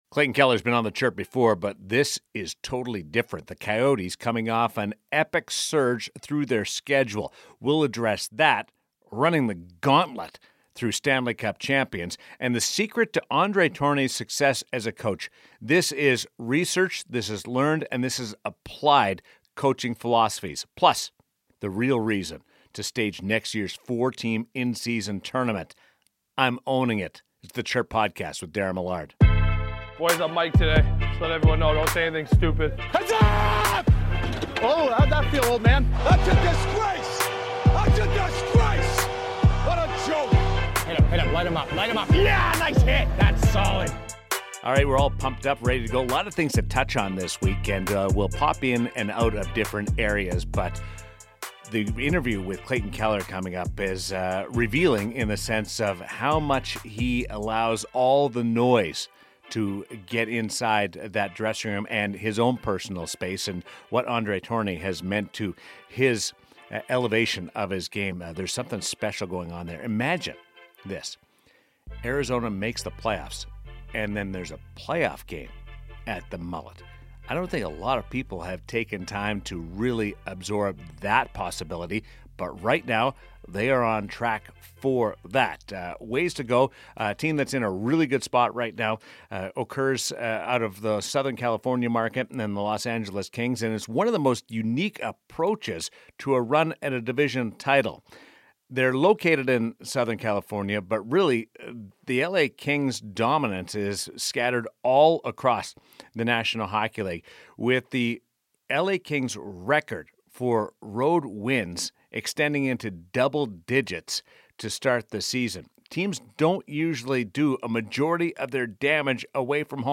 Daren is joined by Clayton Keller (7:43). Keller talks about the Coyotes start to the season, playing for André Tourigny, Connor Ingram's unbelievable return to the NHL and the prospects of playing an in-season international tournament. Daren riffs on the LA Kings remarkable road success, his early choices for the Jack Adams Award and ponders some of the roster construction for international competition.